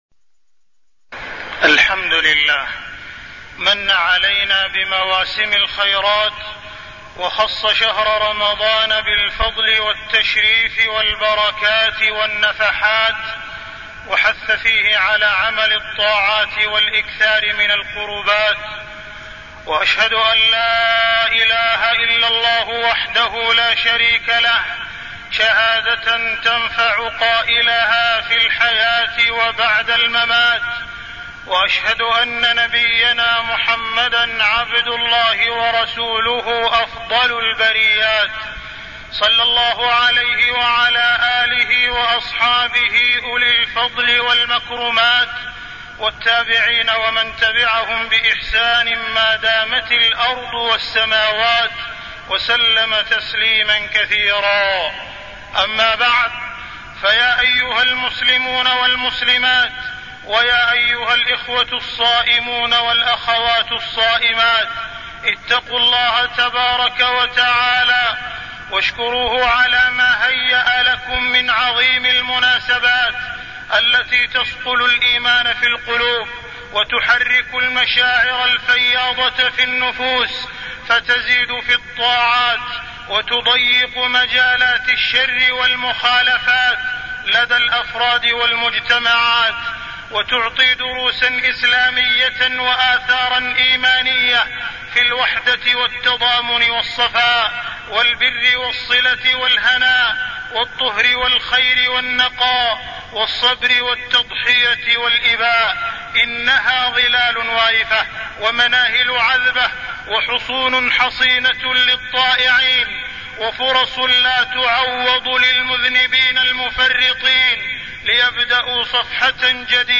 تاريخ النشر ٩ رمضان ١٤٢٠ هـ المكان: المسجد الحرام الشيخ: معالي الشيخ أ.د. عبدالرحمن بن عبدالعزيز السديس معالي الشيخ أ.د. عبدالرحمن بن عبدالعزيز السديس رمضان وقضايا الأمة